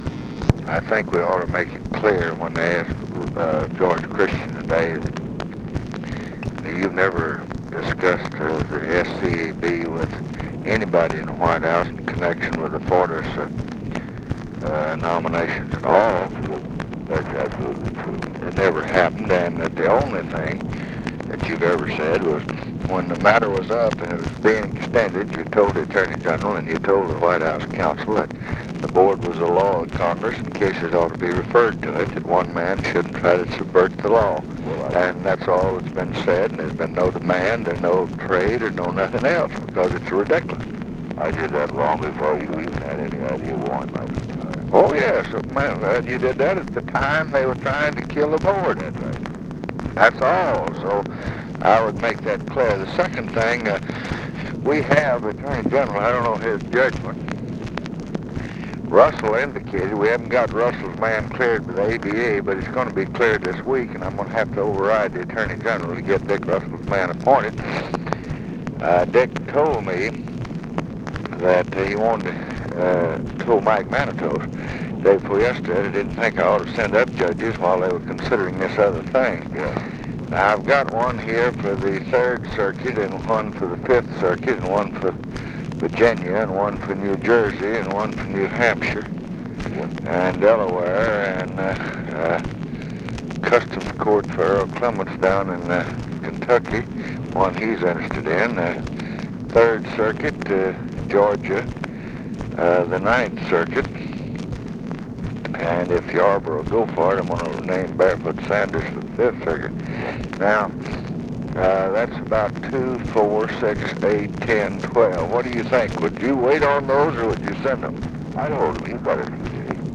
Conversation with EVERETT DIRKSEN, July 11, 1968
Secret White House Tapes